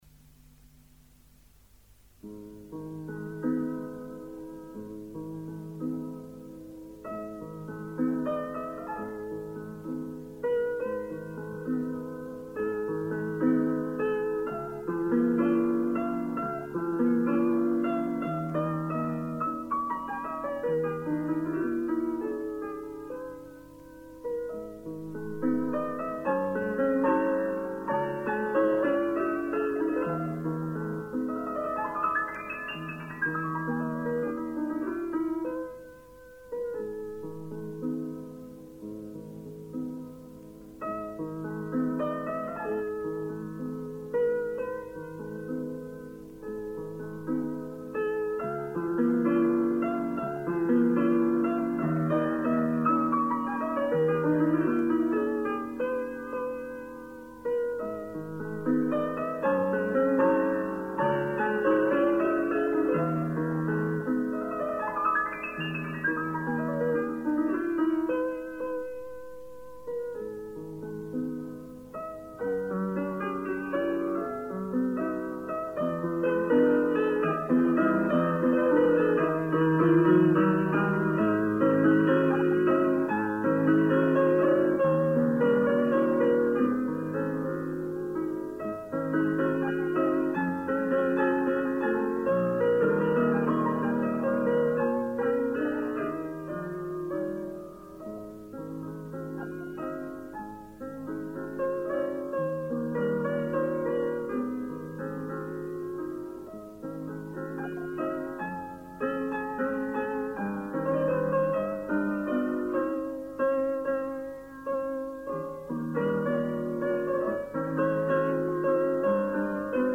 Tipo: Casete sonoro
Intérprete: Isidora Zegers
Materias: Música para Piano